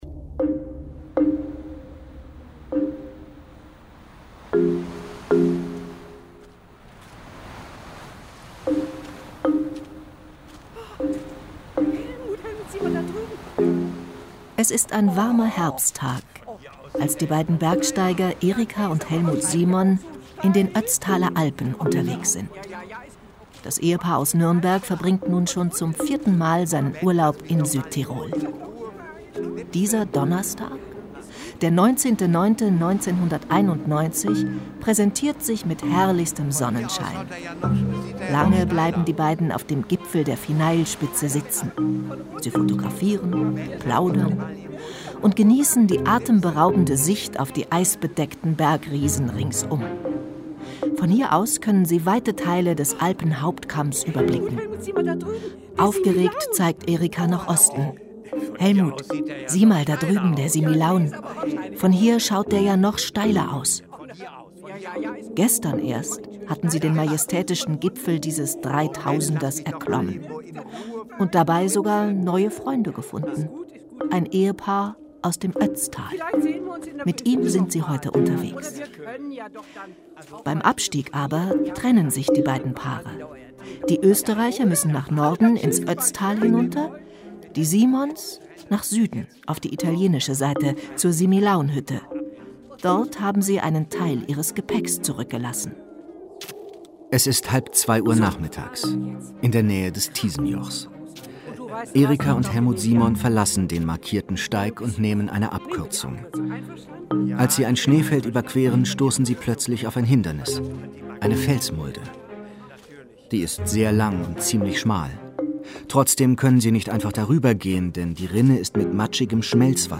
Wissensfeature über den spannenden Fund der Gletschermumie, die der Forschung nicht nur Antworten gibt, sondern auch Fragen stellt